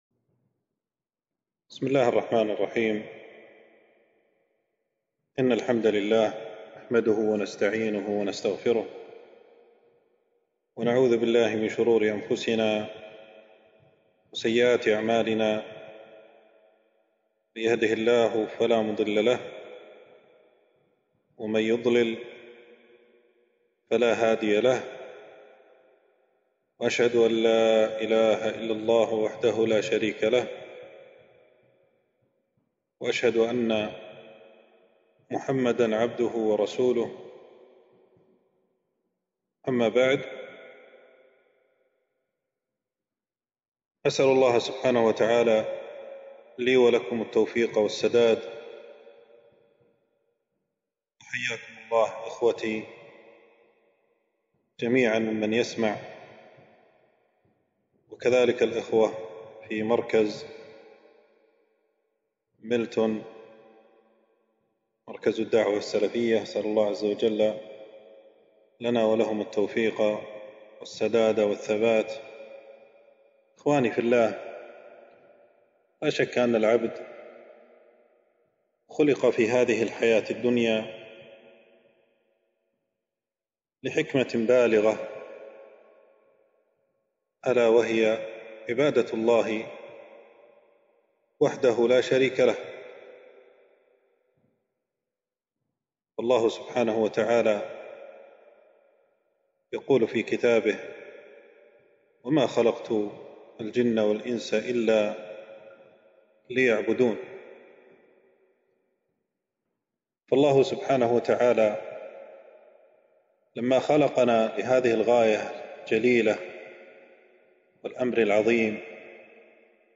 محاضرة - وتوبوا إلى الله جميعاً